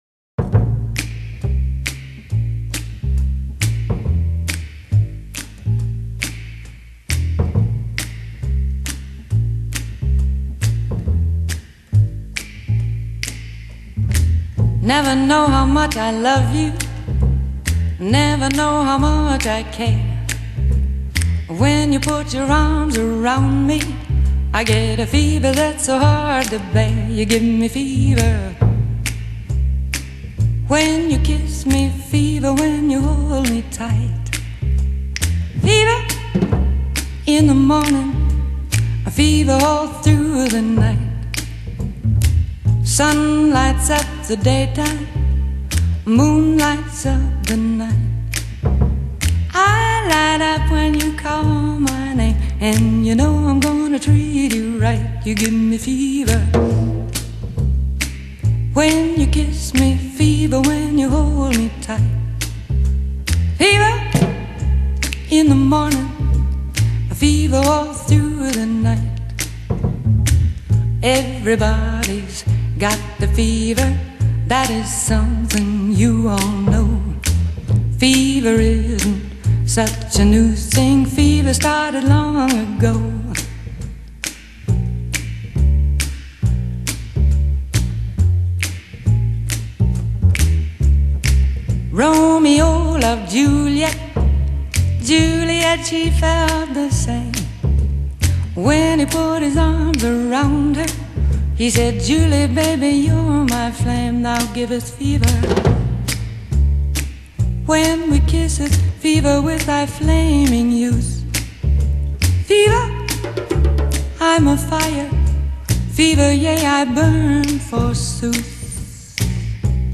Genre: Jazz, Vocal Jazz